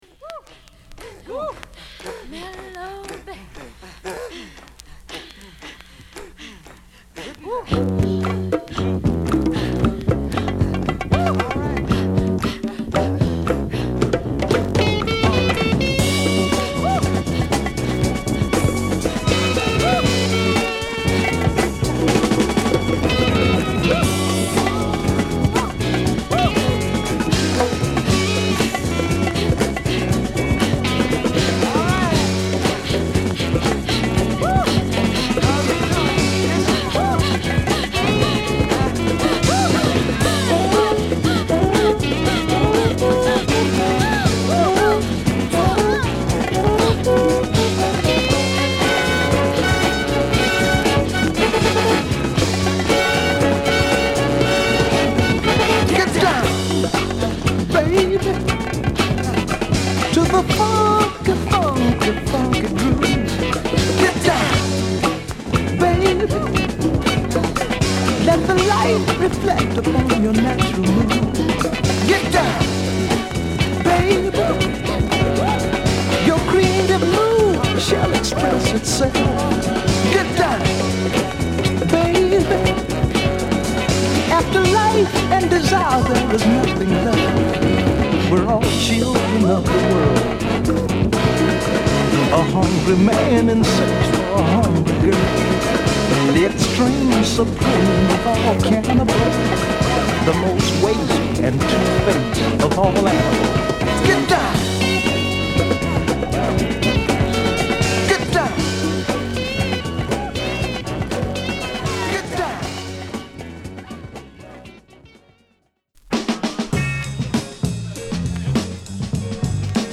＊スチレン盤の音割れ有り